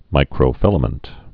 (mīkrō-fĭlə-mənt)